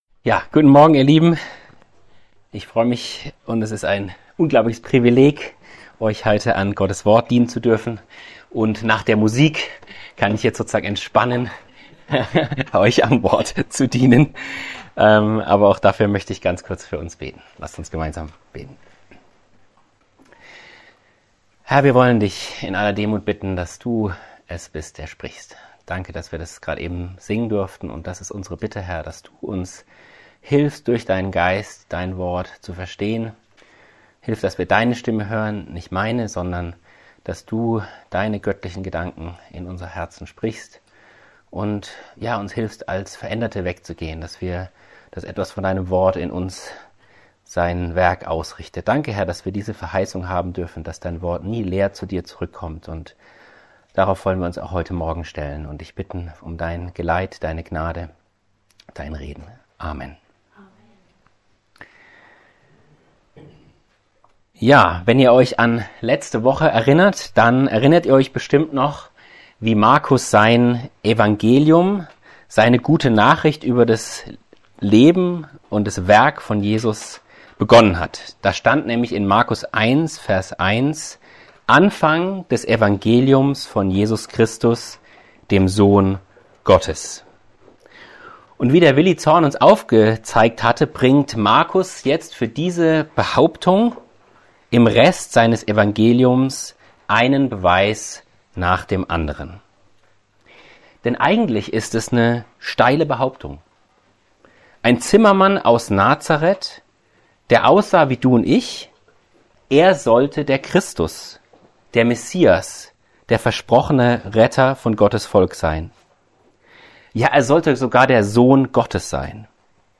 Predigtreihe: Markusevangelium Auslegungsreihe